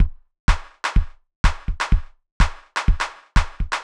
IBI Beat - Mix 10.wav